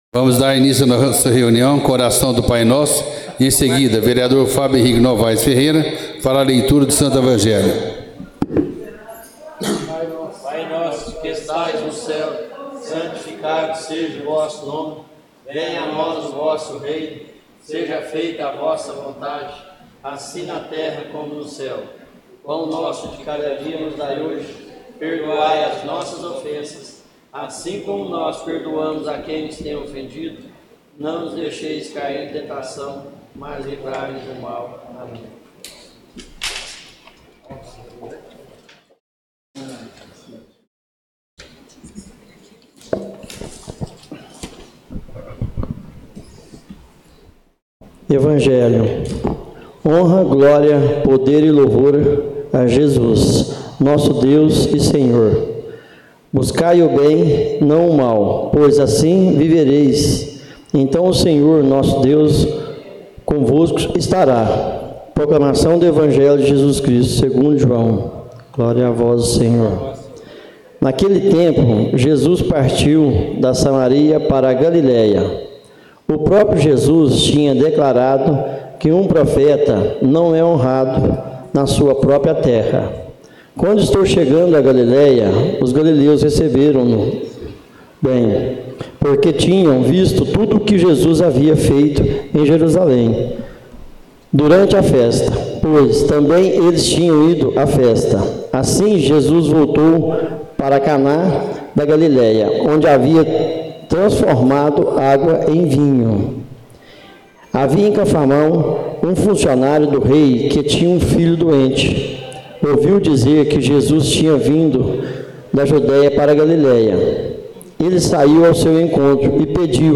Tipo de Sessão: Ordinária